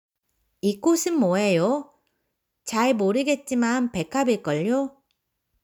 Conversation Audio